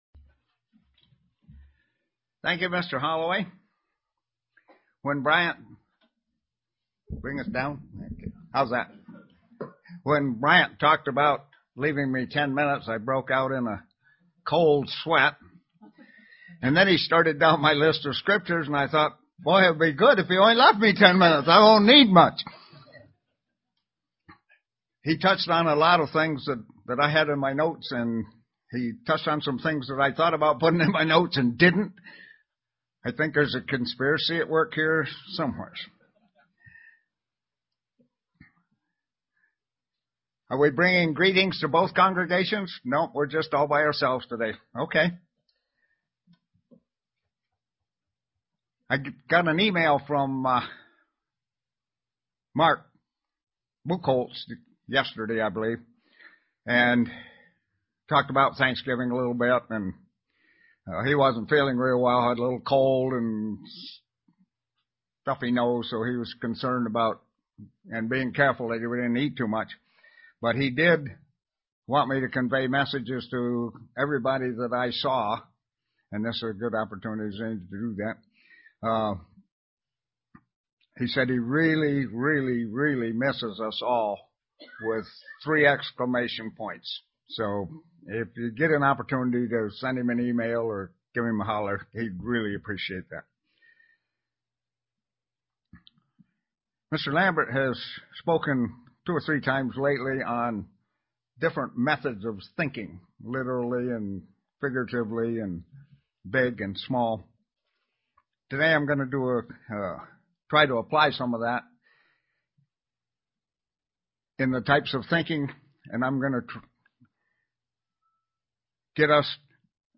Print Who is my brother and how you can tell UCG Sermon Studying the bible?
Given in Elmira, NY